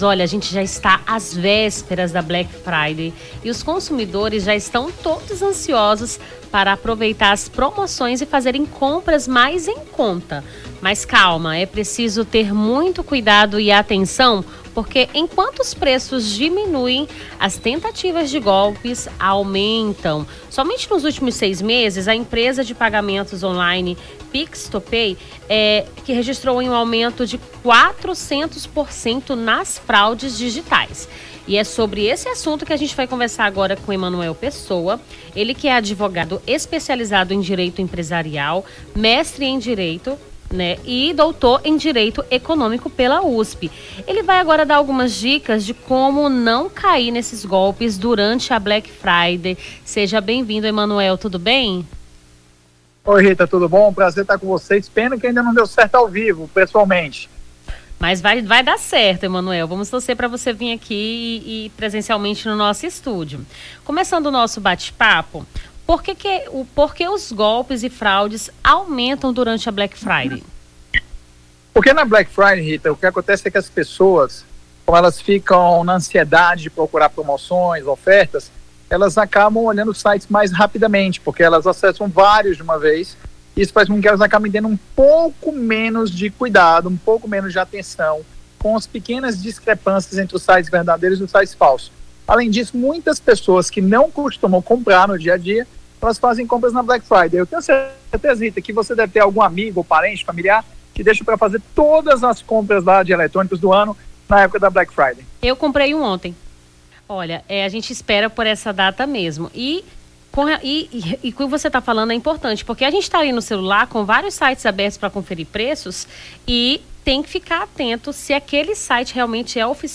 Nome do Artista - CENSURA - ENTREVISTA BLACK FRIDAY GOLPES (28-11-24).mp3